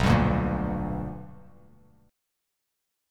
BmM7#5 chord